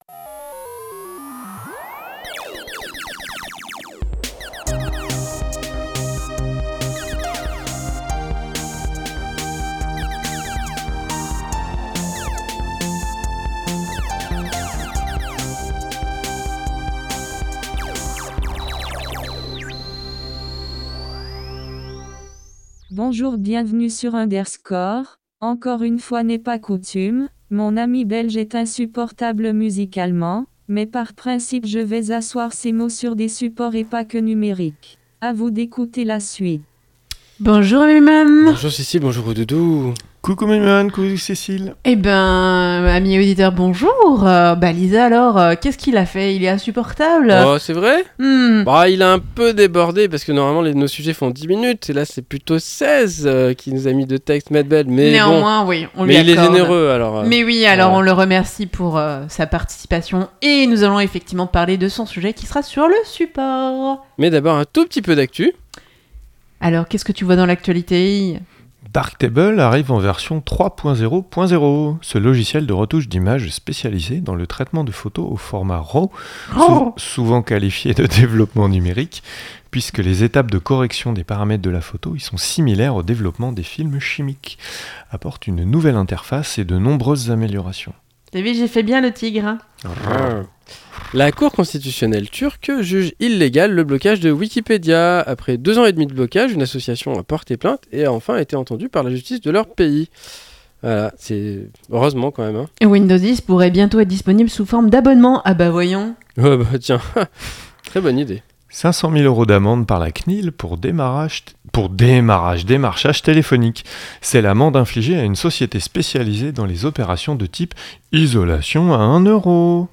L'histoire des supports de la musique De l'actu ; une pause chiptune ; un sujet : L'histoire des supports de la musique ; l'agenda ; et astrologeek !